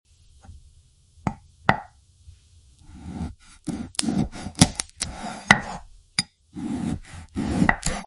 Damascus Knife 🔪 Vs Pistons Sound Effects Free Download